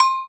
th_sfx_bell_2.ogg